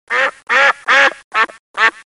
鸭子 | 健康成长
duck-sound.mp3